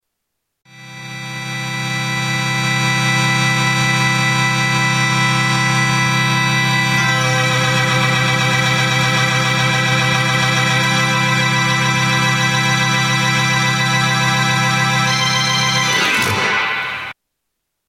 ELKA Panther 300 sound 5
Category: Sound FX   Right: Personal